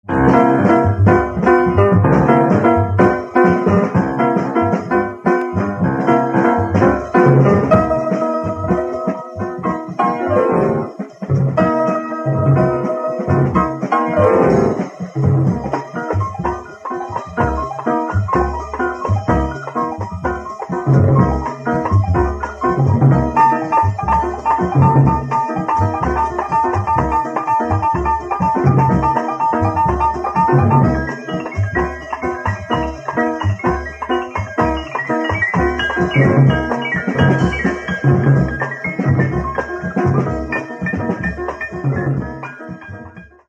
Каталог -> Джаз и около -> Ретро